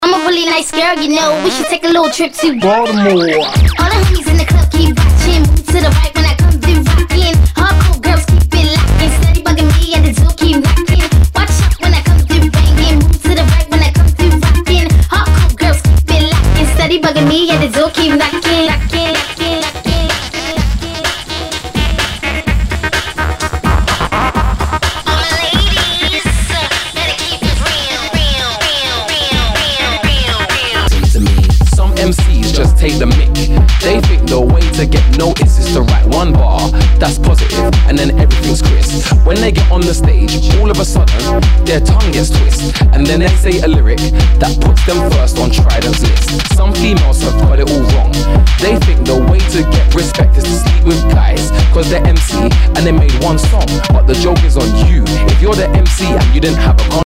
HOUSE/TECHNO/ELECTRO
ナイス！エレクトロ・ヴォーカル・ハウス！
少し盤に歪みあり